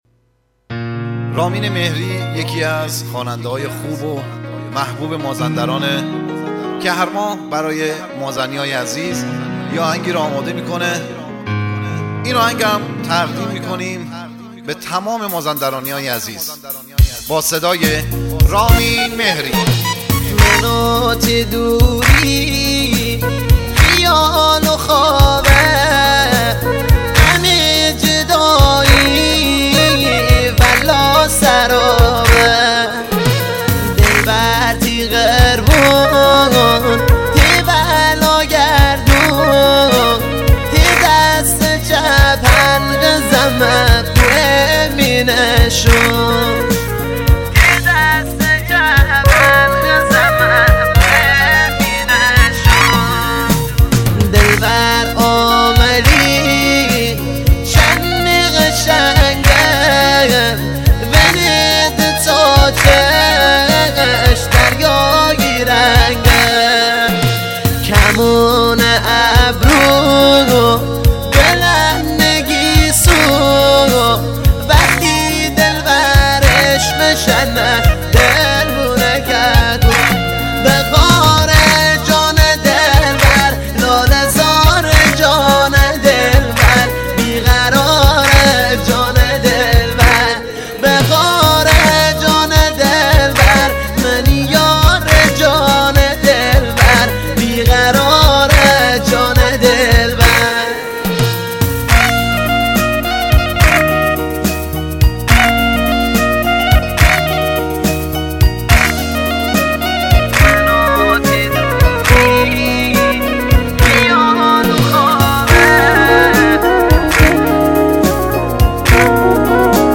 آهنگ مازندرانی